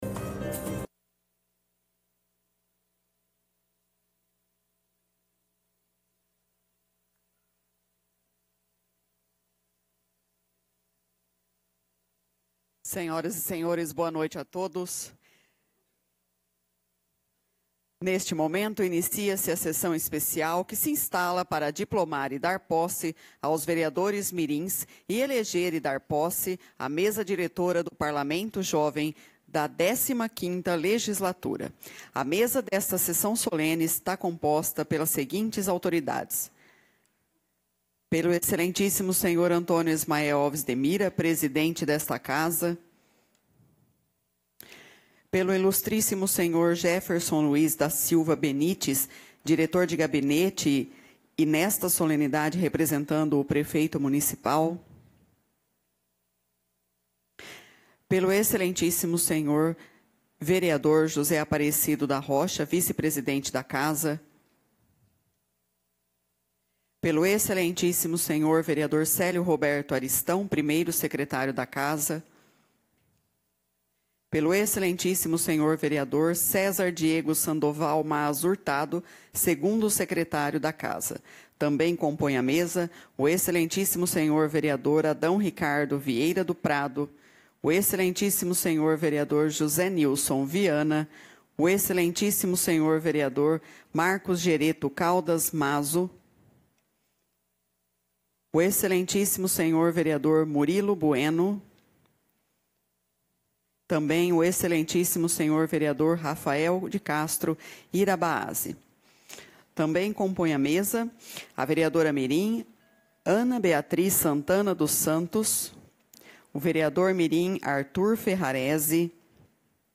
Solenidade de Posse Parlamento Jovem 2026
solenidade-posse-mirins-2026-site.m4a